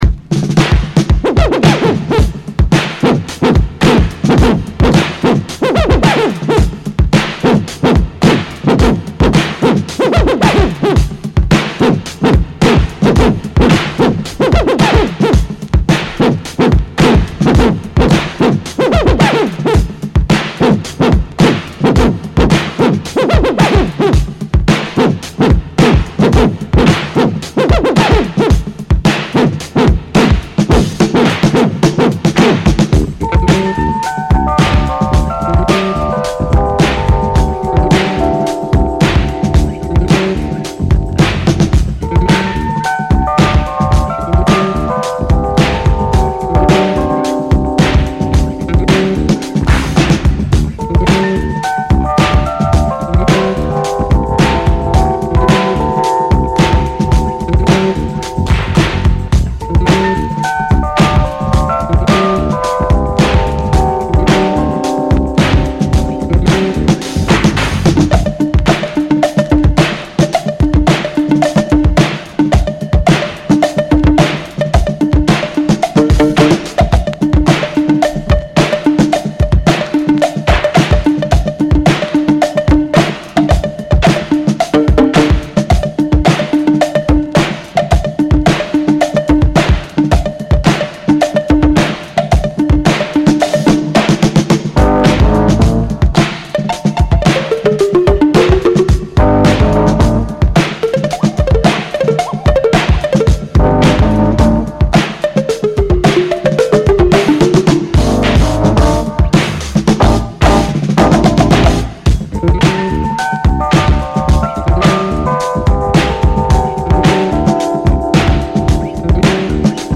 Essential boogie re-issue
includes the dodgy rap version!